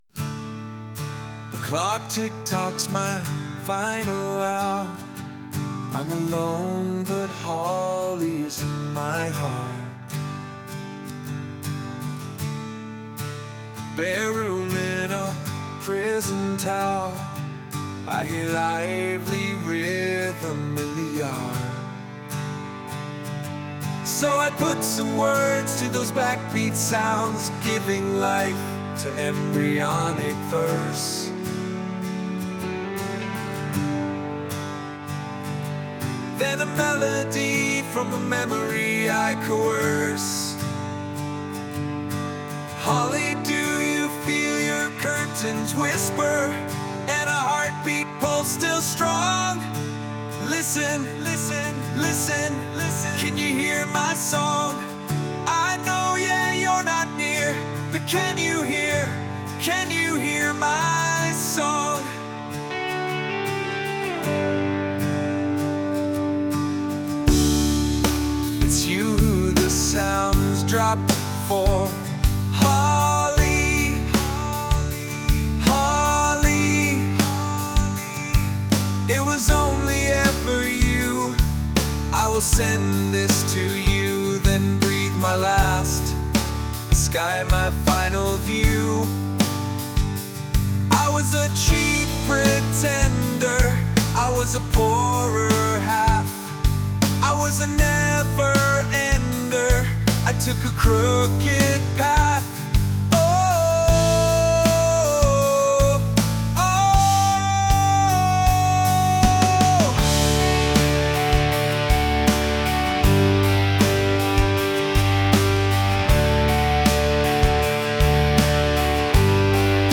I’ve also had a crack at a couple of ai versions…